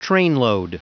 Prononciation du mot trainload en anglais (fichier audio)
Prononciation du mot : trainload